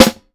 Los Live Snare.wav